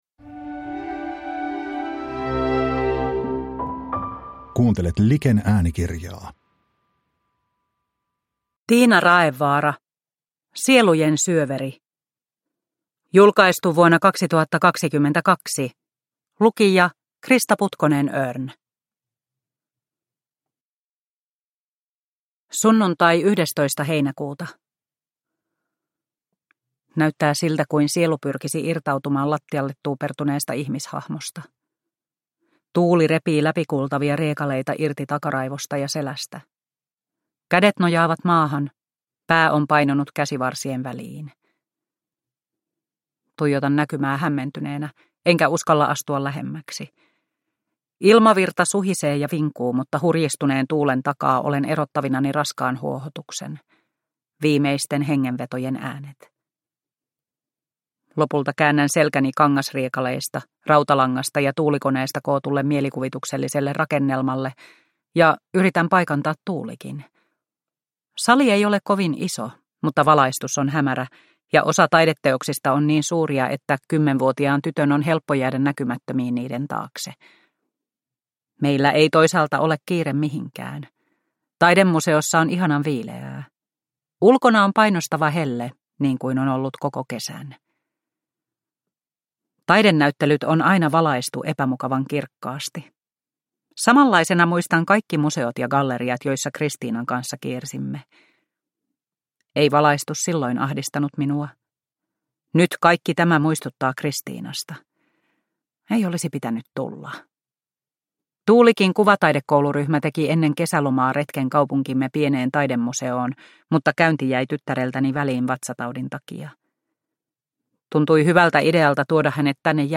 Sielujen syöveri – Ljudbok – Laddas ner